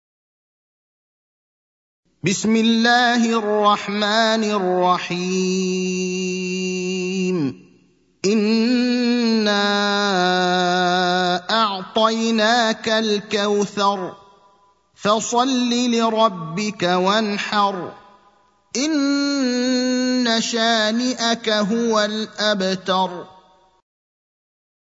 المكان: المسجد النبوي الشيخ: فضيلة الشيخ إبراهيم الأخضر فضيلة الشيخ إبراهيم الأخضر الكوثر (108)